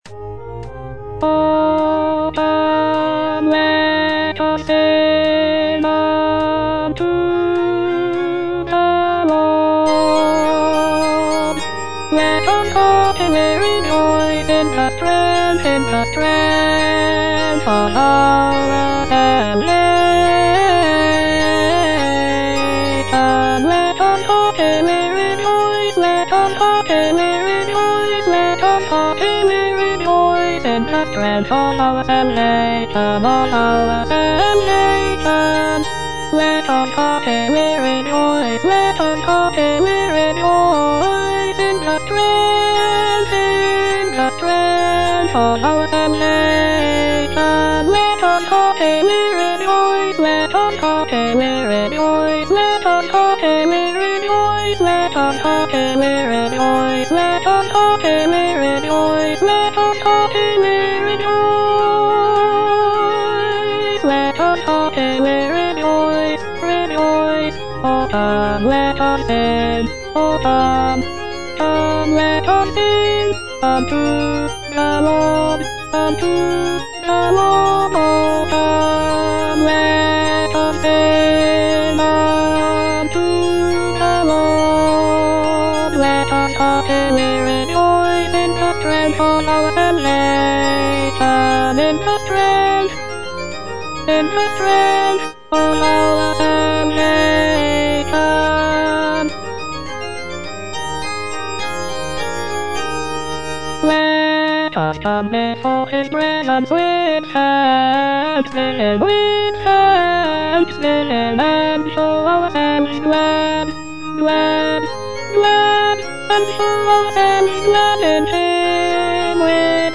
Choralplayer playing O come, let us sing unto the Lord - Chandos anthem no. 8 HWV253 (A = 415 Hz) by G.F. Händel based on the edition CPDL #09622
The use of a lower tuning of A=415 Hz gives the music a warmer and more resonant sound compared to the standard tuning of A=440 Hz.
G.F. HÄNDEL - O COME, LET US SING UNTO THE LORD - CHANDOS ANTHEM NO.8 HWV253 (A = 415 Hz) O come, let us sing unto the Lord - Alto (Voice with metronome) Ads stop: auto-stop